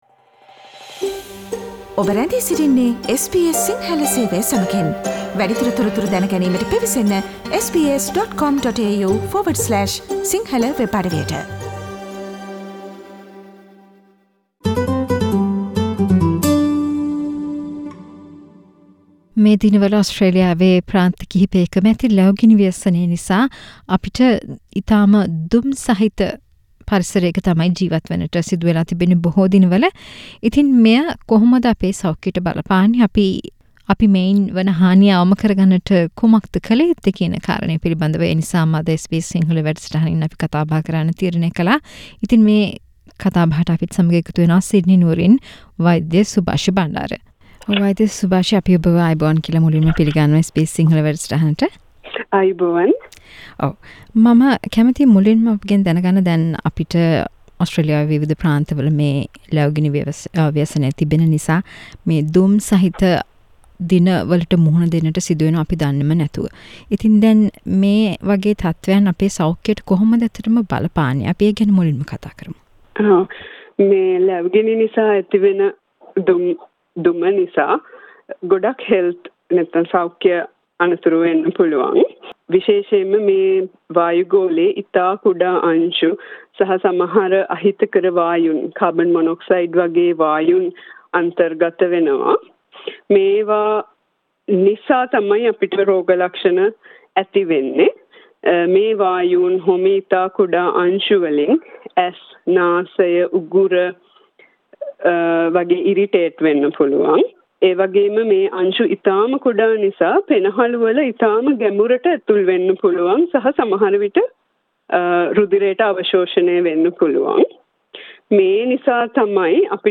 SBS සිංහල සිදු කල සාකච්චාවක්